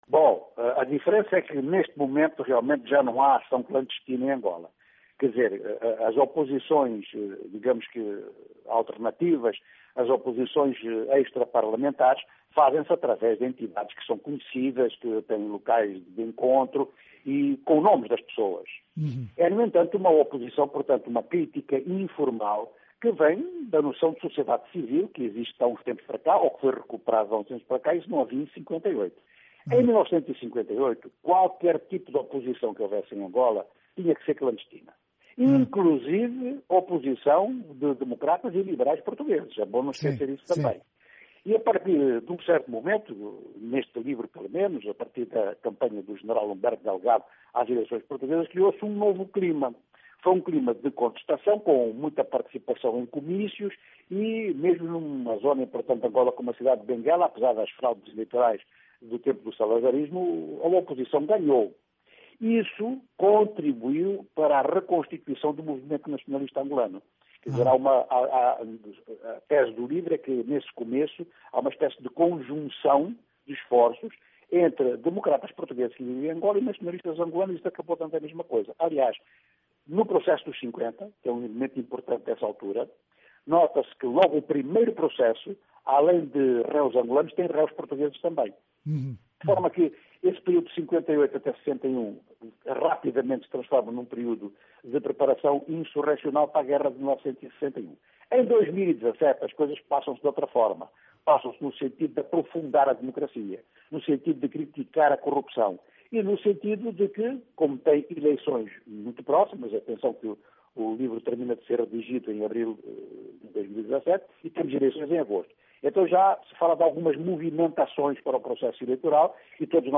Livro sobre a luta pela democracia em Angola - entrevista - 8:36